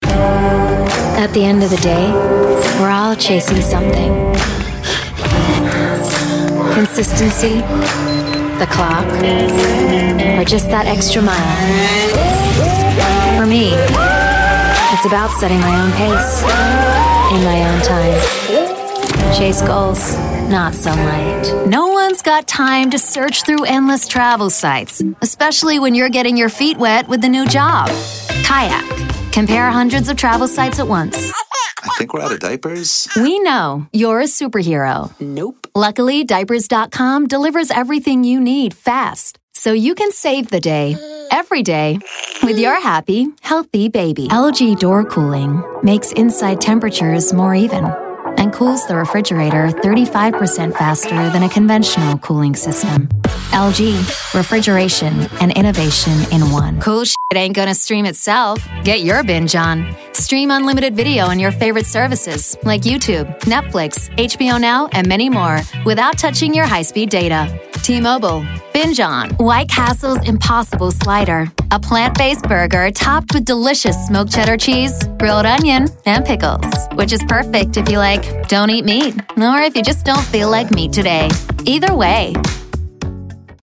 Demonstração Comercial
Sou uma dubladora experiente que oferece direção de voz e produção de áudio com qualidade de transmissão.
Neumann U87
Soprano